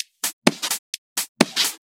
Index of /VEE/VEE Electro Loops 128 BPM
VEE Electro Loop 281.wav